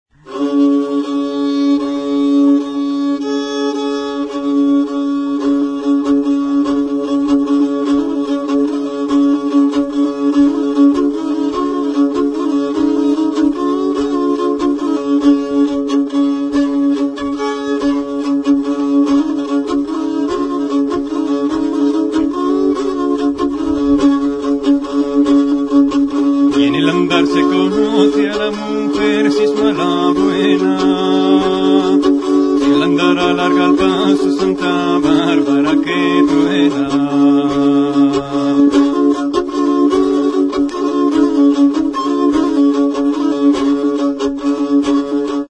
RABEL | Soinuenea Herri Musikaren Txokoa
EN EL ANDAR SE CONOCE. Jota a lo pesado.
HM udako kontzertua.
Bi sokako rabela da.